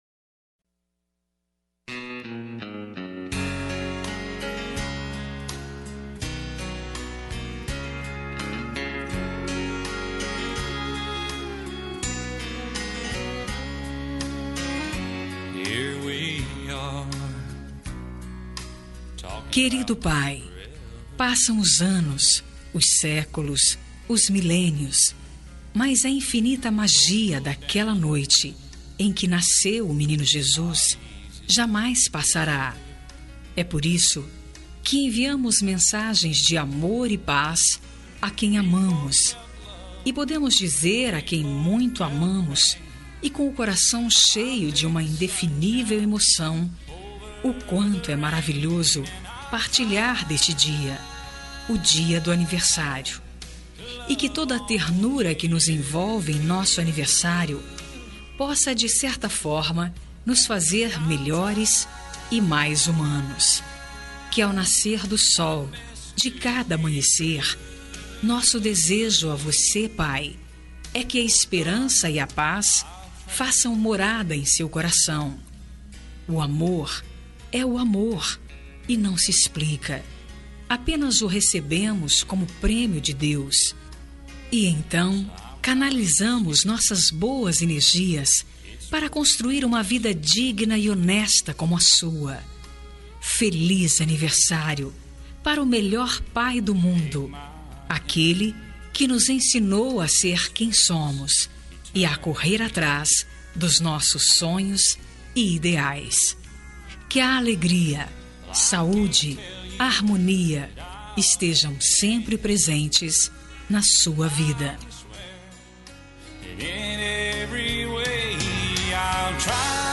Aniversário de Pai -Voz Feminina – Cód: 11630 – Plural
11630-pai-fem-plural.m4a